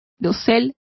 Complete with pronunciation of the translation of dais.